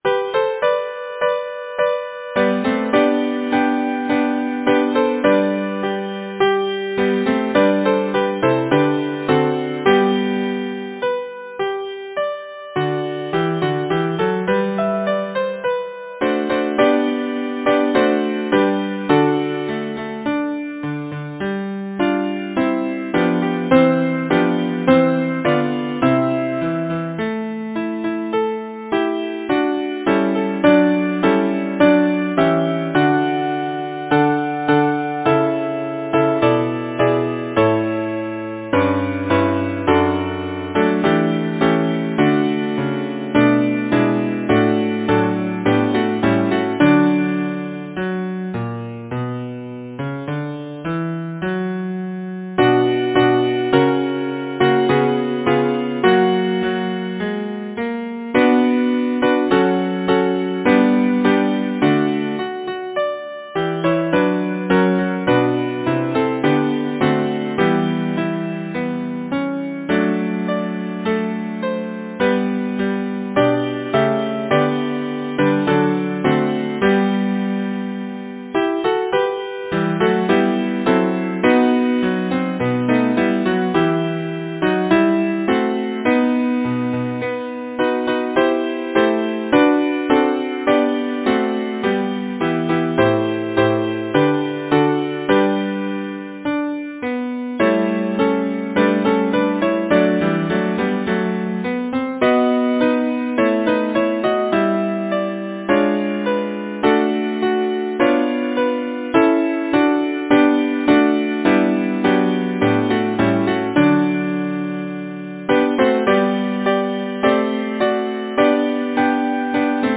Title: The Shepherd’s Choice Composer: Alexandra Thomson Lyricist: William Browne Number of voices: 4vv Voicing: SATB Genre: Secular, Partsong, Madrigal
Language: English Instruments: A cappella